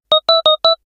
powerUp12.ogg